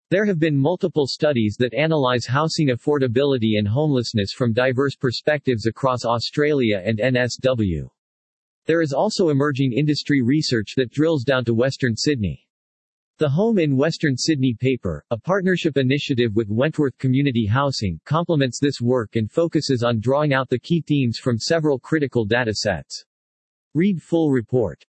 Text to speech